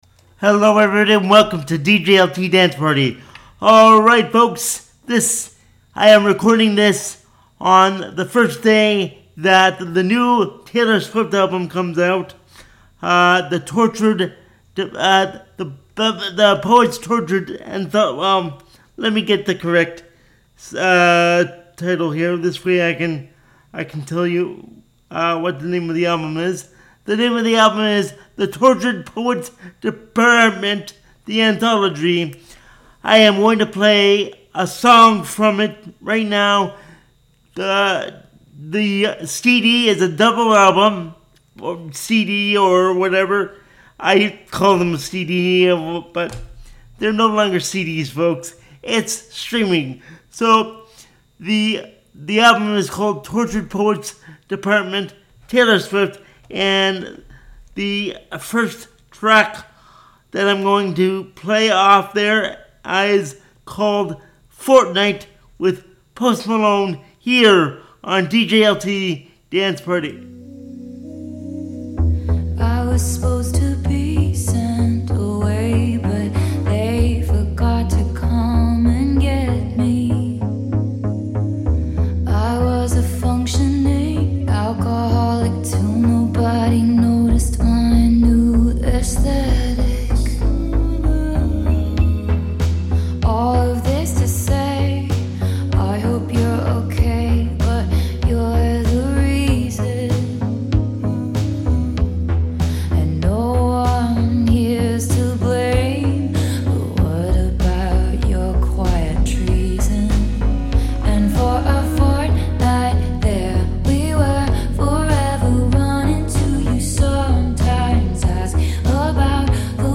If NOT it is the CLEAN Radio Version.